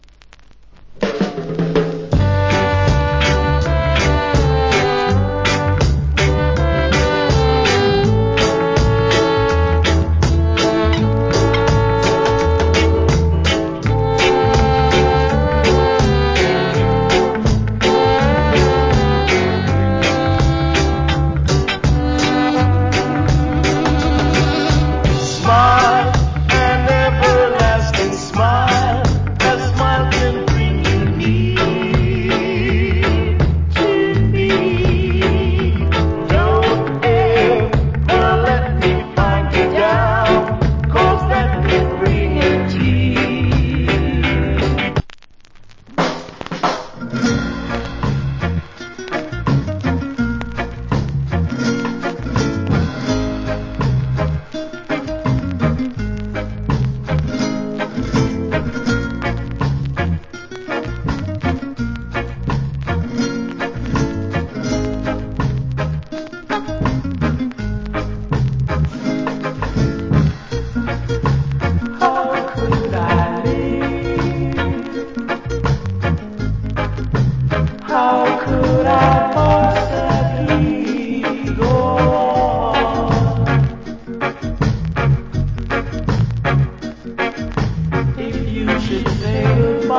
Nice Duet Rock Steady.